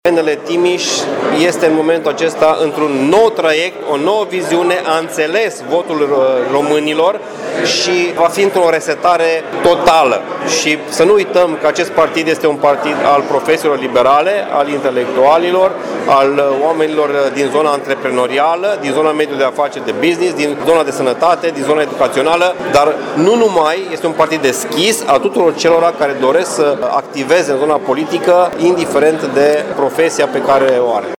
Liberalii din Timiș au așteptat anunțarea primelor rezultate exit-poll la sediul din Piața Unirii.